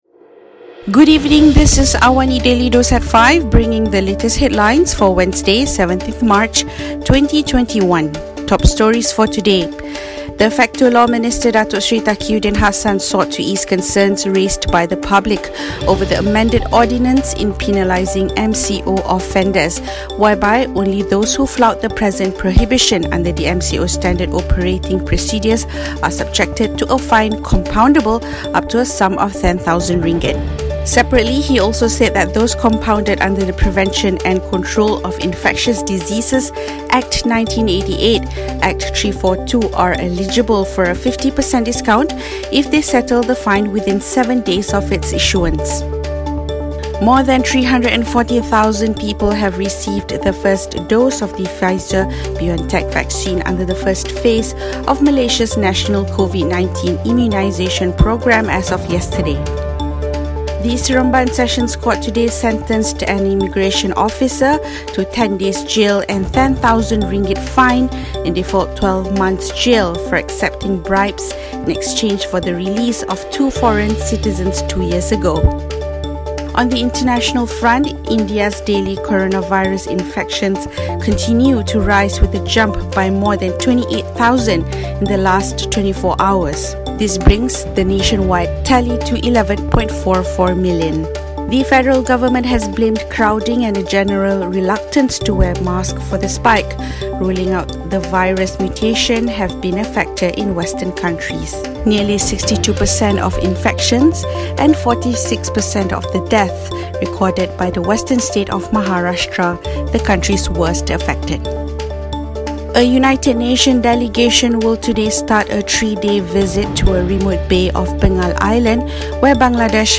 Listen to the top stories of the day, reporting from Astro AWANI newsroom — all in 3 minutes. We bring you the headlines, weekdays at 5 pm.